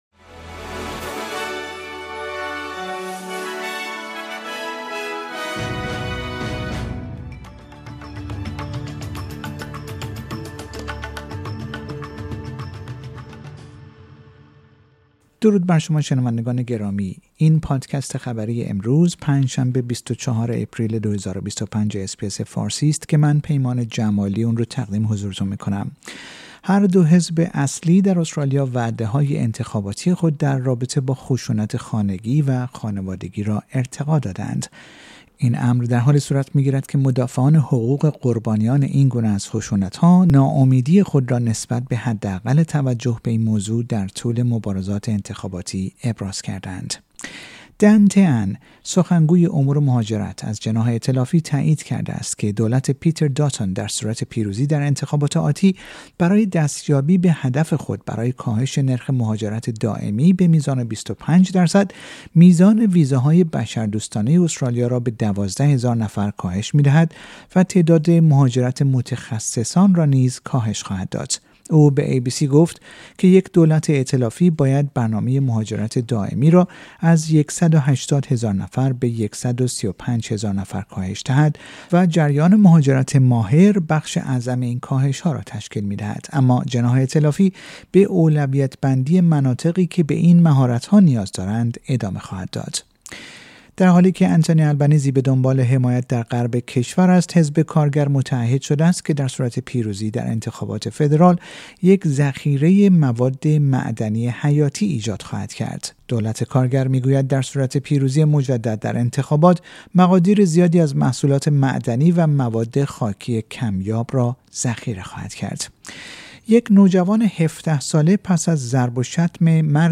در این پادکست خبری مهمترین اخبار امروز پنج شنبه ۲۴ آپریل ارائه شده است.